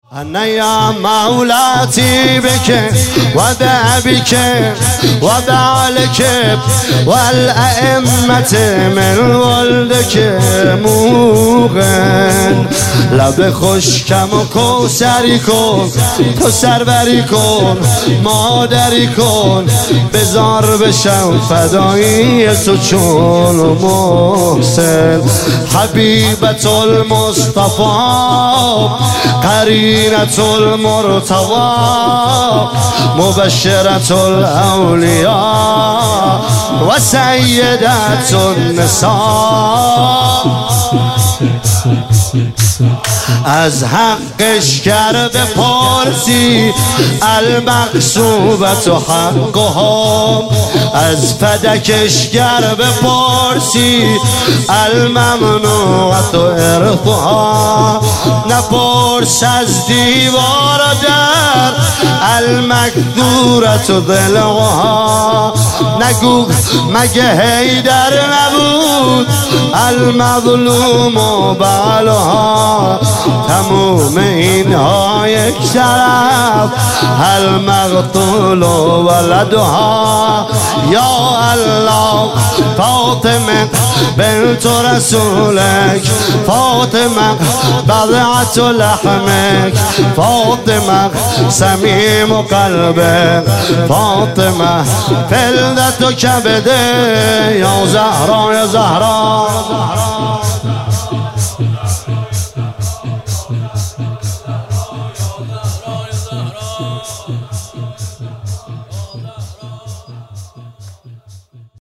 عنوان شب چهاردهم ماه مبارک رمضان ۱۳۹۸
شور انا یا مولاتی بک